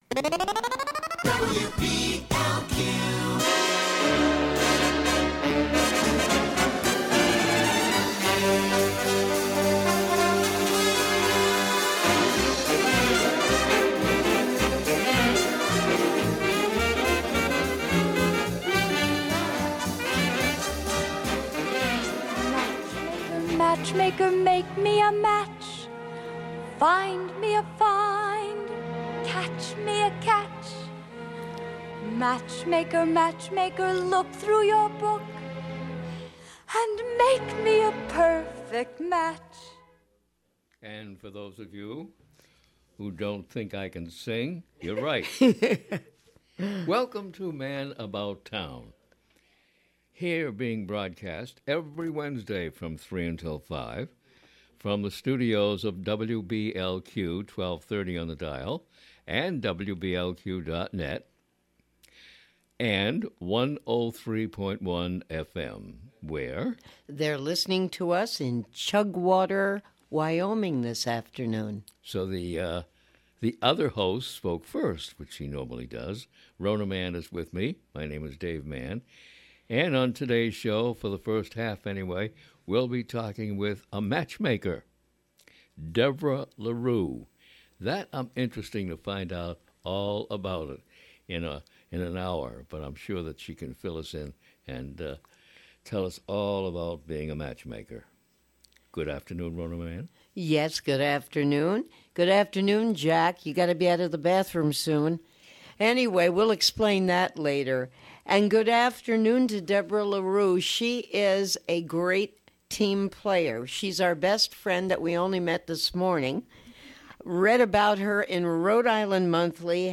Interview_WBLQ.mp3